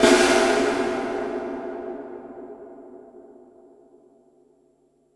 Big Drum Hit 10.wav